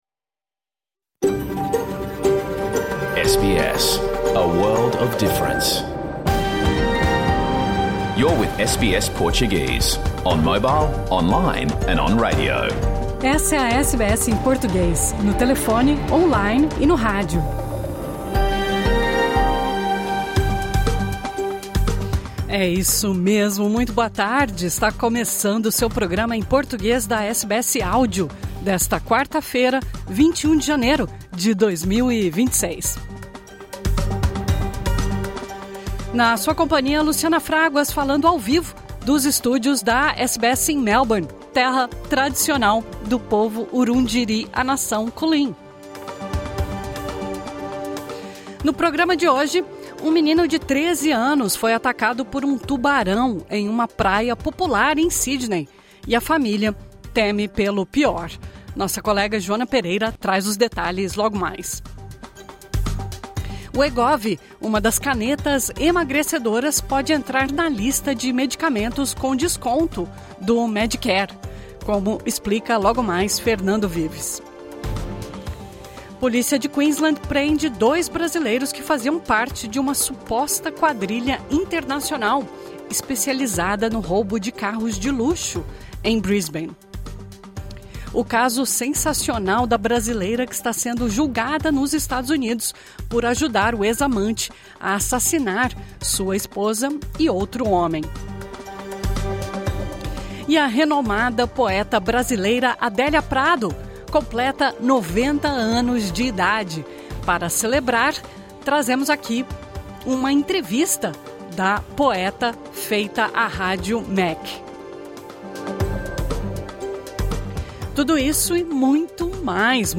Programa ao vivo | Quarta-feira 21 de janeiro